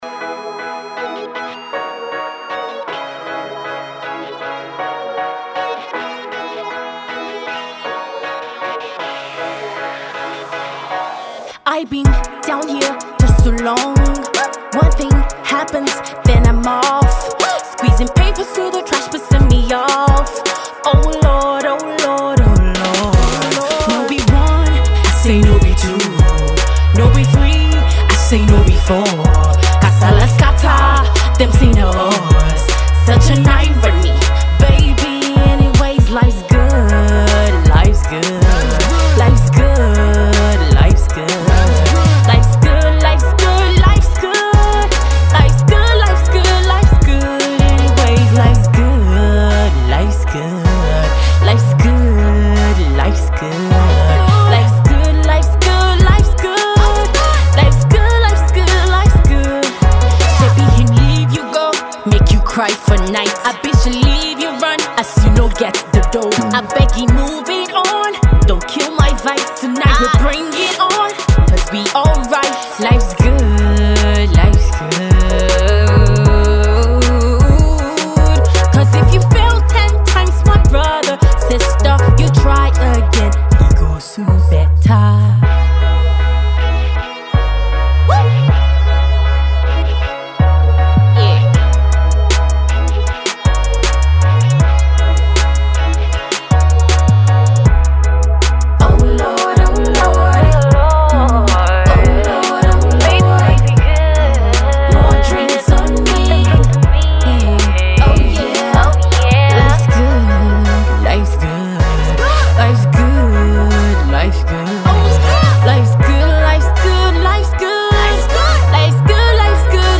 New school pop